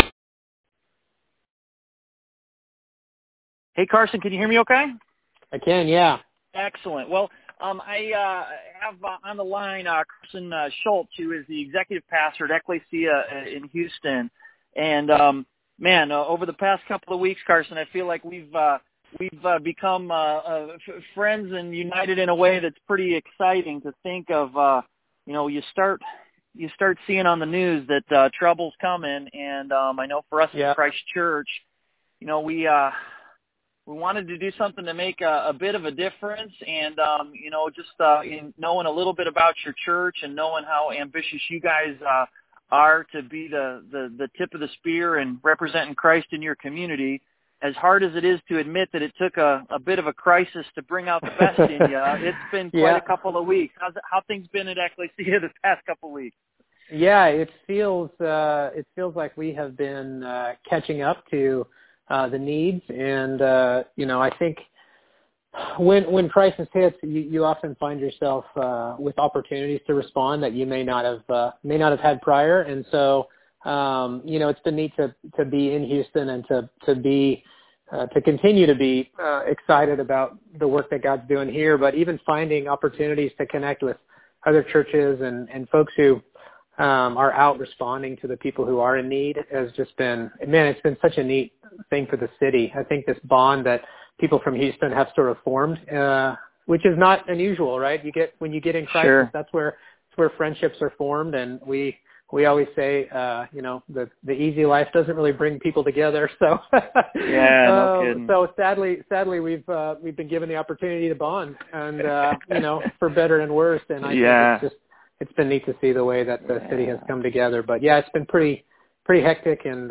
Phone Interview - Houston Flood Relief